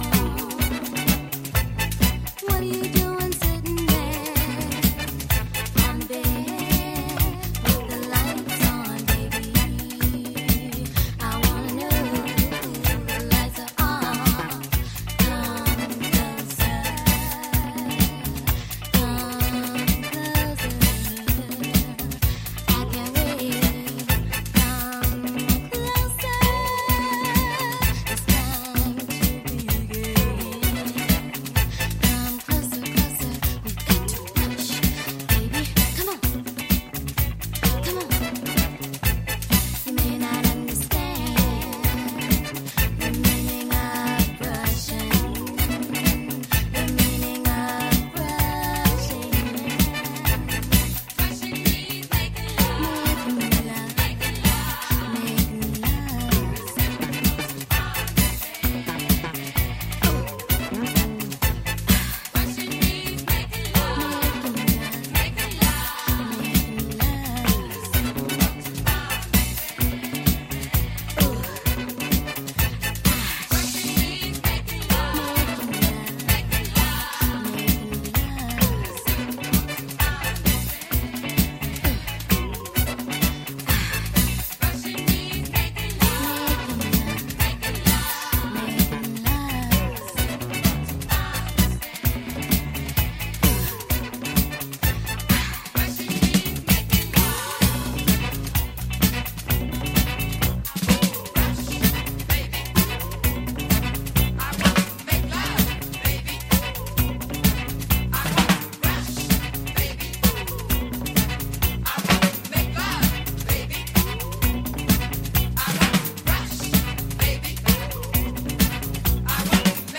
女性ヴォーカルをフィーチャーしたDJ的にもプレイしやすいグルーヴィーなアフロ・ディスコ〜ファンクを展開！